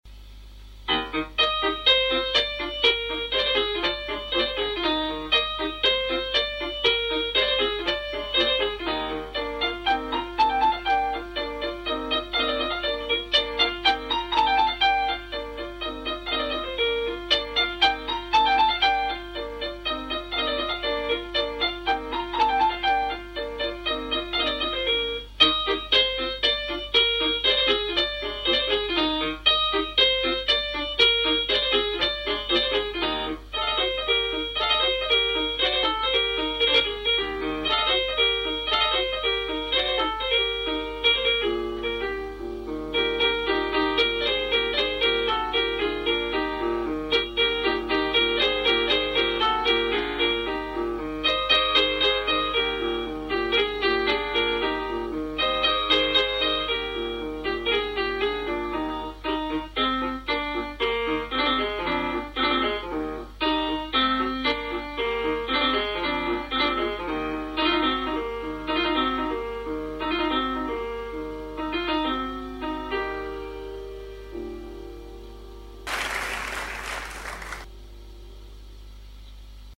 Danza che i contadini ballano a S. Leo nella Festa di S. Cono